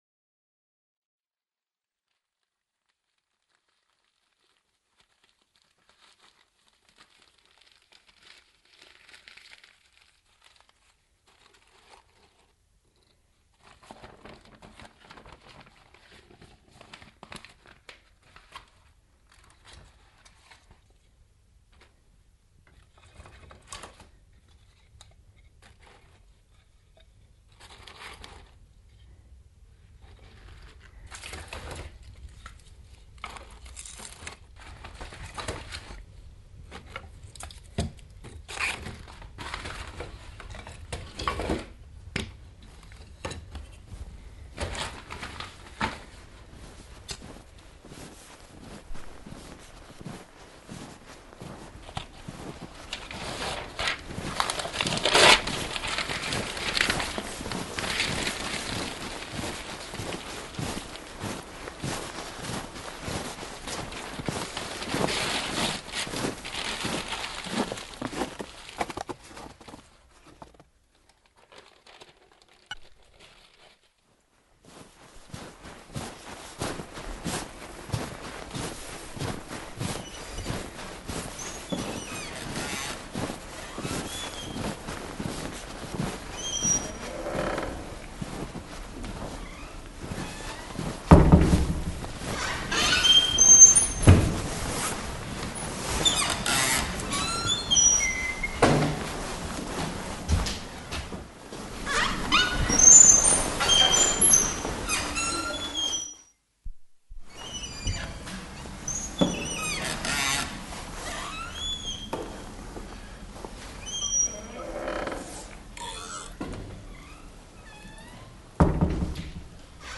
Gentle Fire, Sonic Art Oxford, February 27th 2010
Collect, on tape, examples of ambient sound events such as those made by